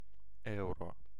Ääntäminen
France (Paris): IPA: [ø.ʁo]